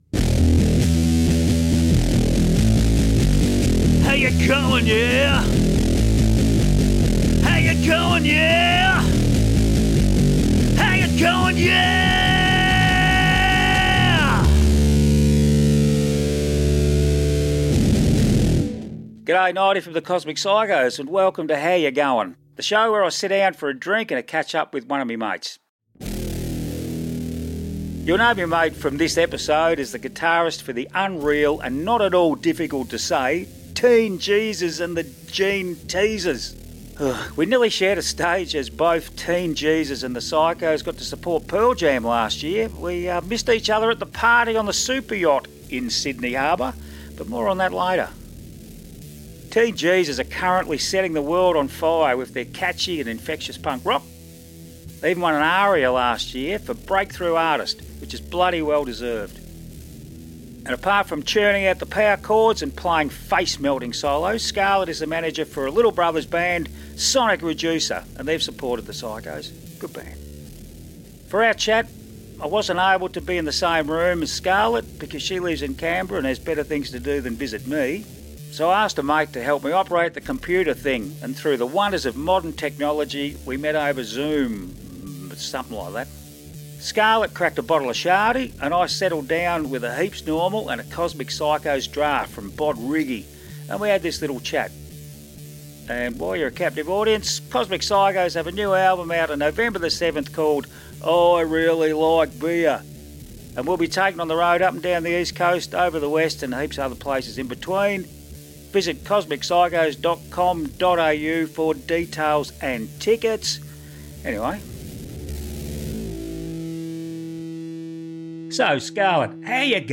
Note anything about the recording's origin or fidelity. So I asked a mate to help me operate a computer and through the wonders of modern technology, we met over Zoom.